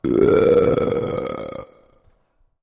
Звуки пьяного человека
Алкаш напился и рыгнул воздухом